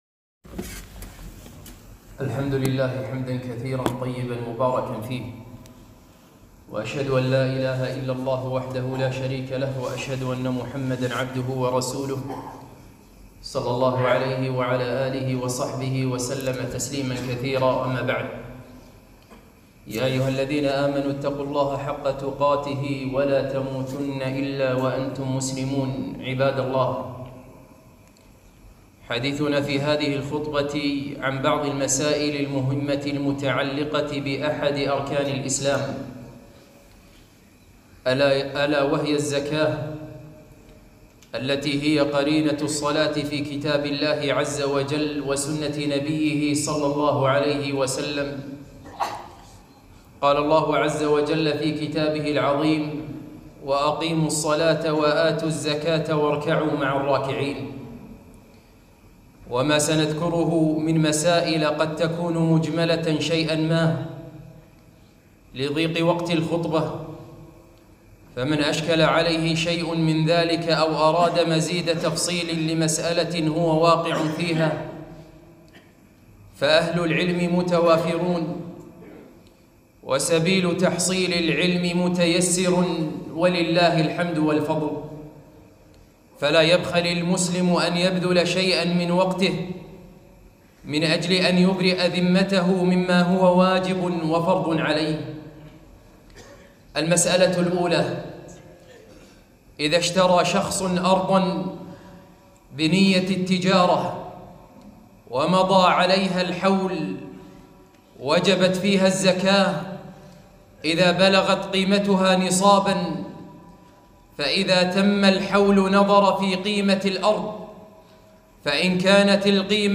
خطبة - اثنتا عشرة مسألة متعلقة بالزكاة - دروس الكويت